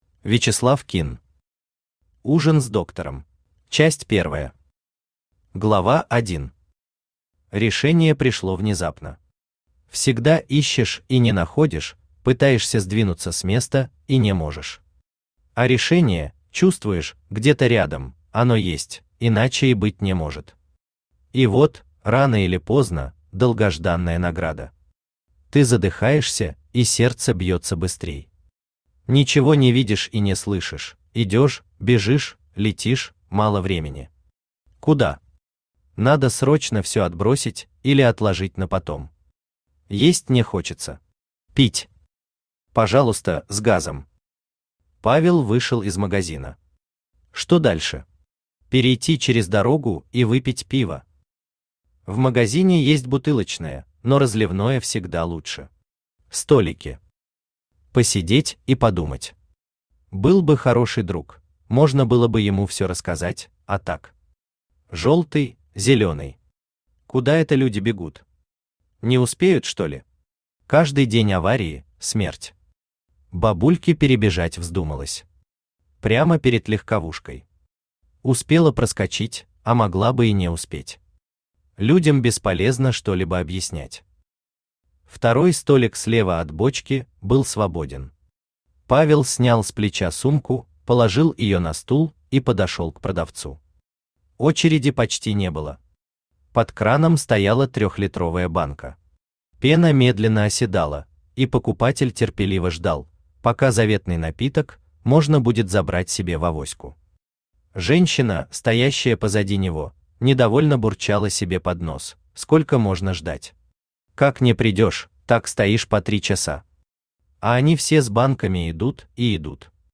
Аудиокнига Ужин с Доктором | Библиотека аудиокниг
Aудиокнига Ужин с Доктором Автор Вячеслав Кинн Читает аудиокнигу Авточтец ЛитРес.